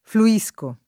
flu-&Sko], ‑sci — ger. fluendo [flu-$ndo] — es. poet.: de la pronta anima scossa Dal dio che per le vene a te fluia [